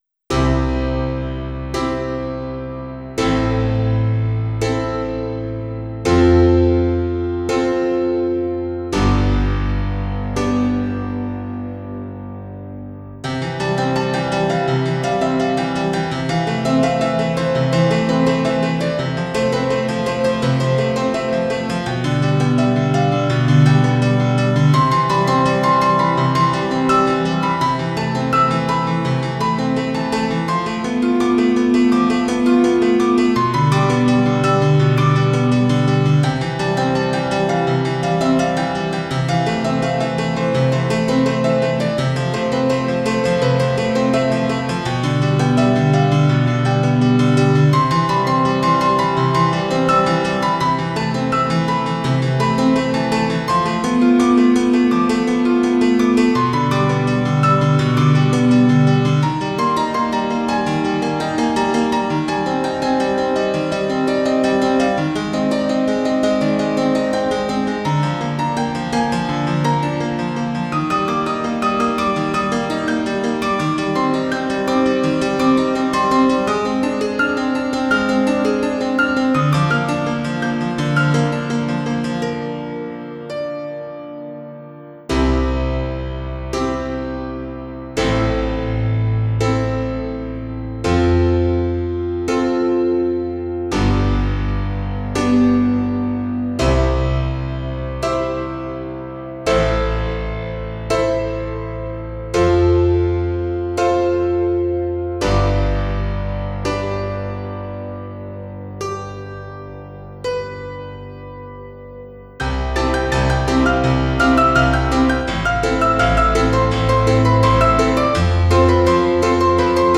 PIANO T-Z (21)